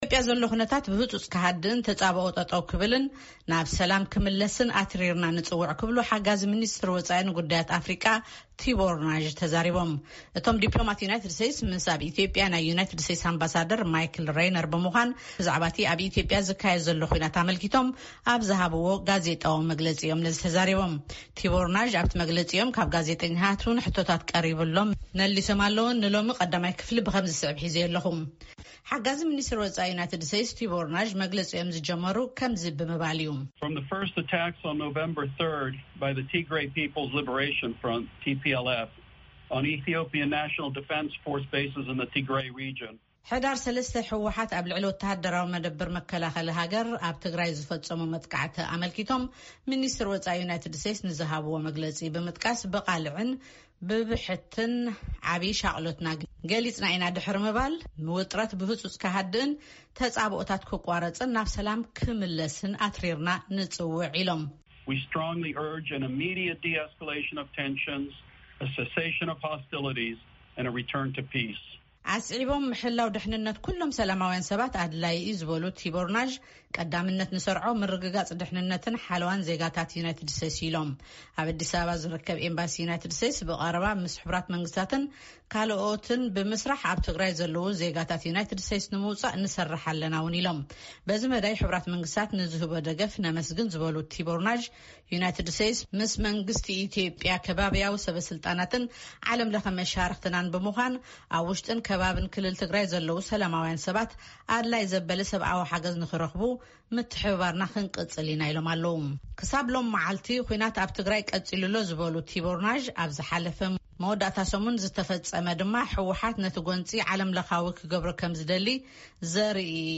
እቶም ኣሜሪካዊ ዲፕሎማት ምስ ኣምባሳደር ኣሜሪካ ኣብ ኢትዮጵያ ማይክል ረይነር ብምዃን ብዛዕባ’ቲ ኣብ ኢትዮጵያ ዝካየድ ዘሎ ኹናት ኣመልኪቶም ኣብ ዝሃብዎ ጋዜጣዊ መግለጺ’ዩ ም ነዚ ዝበሉ።
ቲቦር ናዥ ኣብቲ መግለጺ ካብ ጋዜጠኛታት ዝቐረበሎም ሕቶታት'ውን መሊሶም’ዮም።